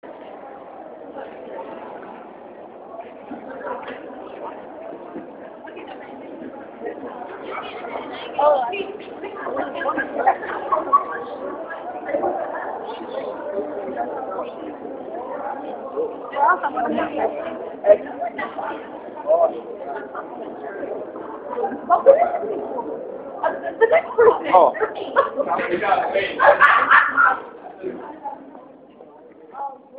Field Recording 2
Walking Through the Studen Center Location: Student Center Sounds heard: Footsteps, many voices, laughter
Sounds heard: Footsteps, many voices, laughter